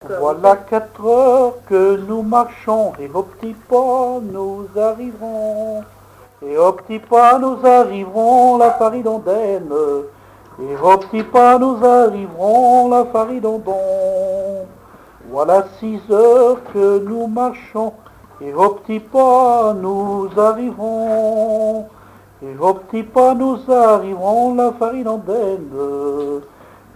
circonstance : fiançaille, noce
Genre énumérative
Catégorie Pièce musicale inédite